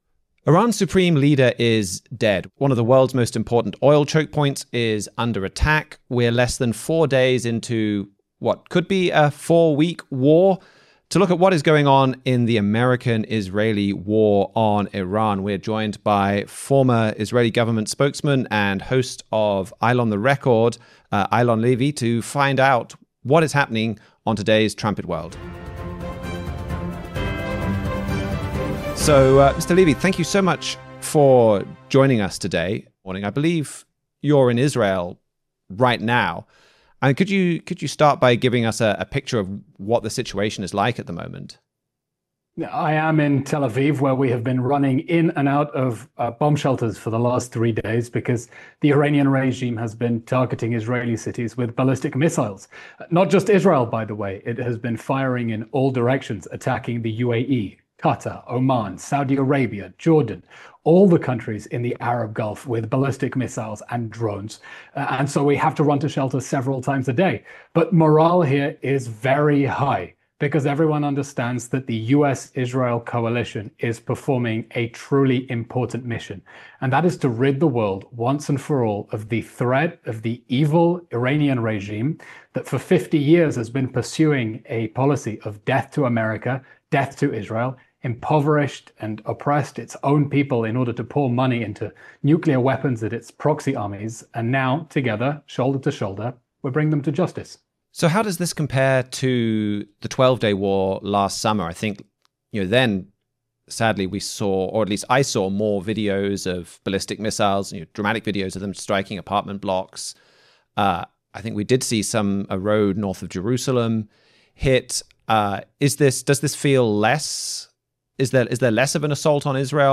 Levy, a former Israeli national spokesman, is in Tel Aviv to provide an on-the-ground update of the situation.